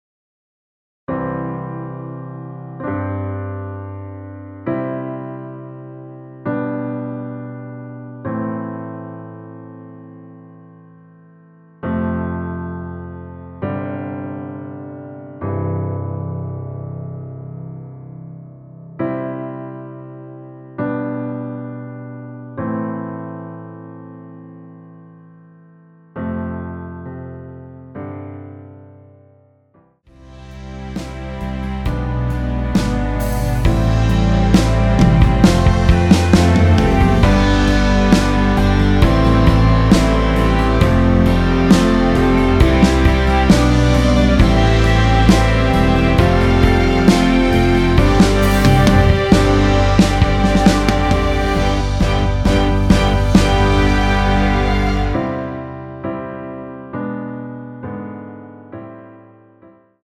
전주없이 시작하는 곡이라 1마디 전주 만들어 놓았습니다.(미리듣기 참조)
원키에서(-1)내린 MR입니다.
앞부분30초, 뒷부분30초씩 편집해서 올려 드리고 있습니다.